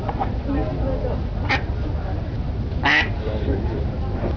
Abb. 15: Stockente bettelt um Futter (FB
Abb. 15: Stockente, wenig ausgeprägt, breites Frequenzband bis in den hohen Bereich,